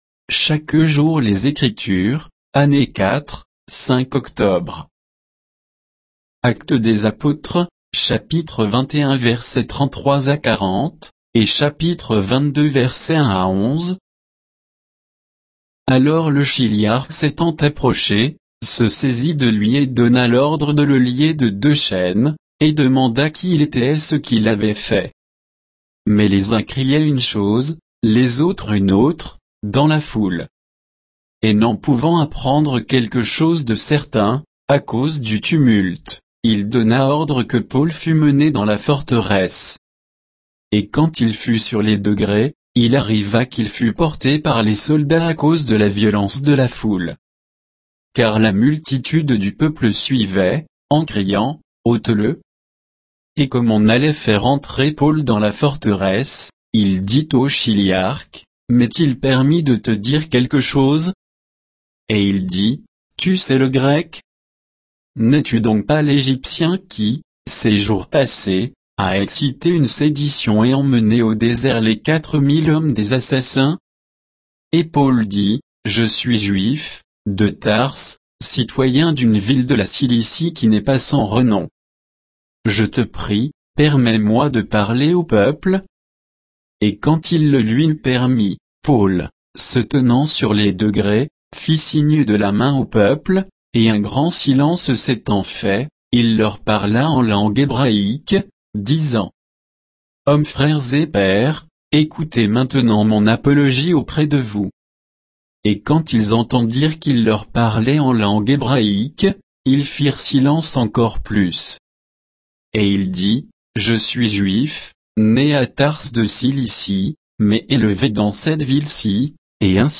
Méditation quoditienne de Chaque jour les Écritures sur Actes 21